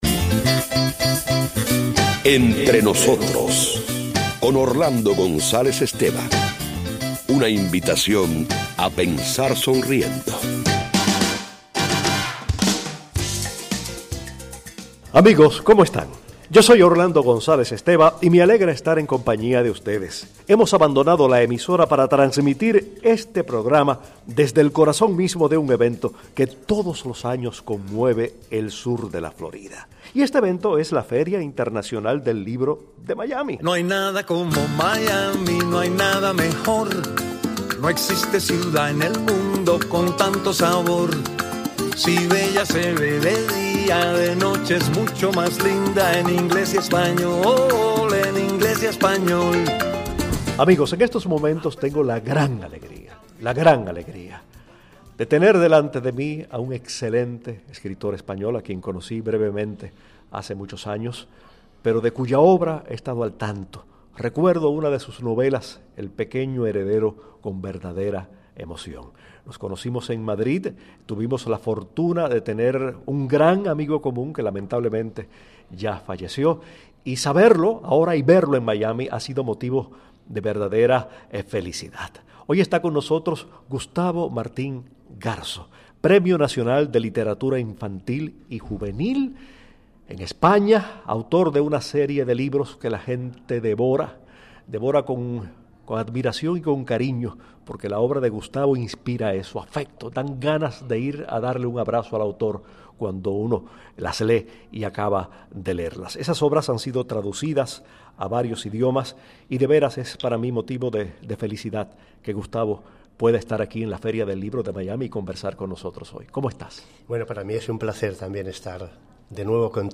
Entrevistas a Gustavo Martín Garzo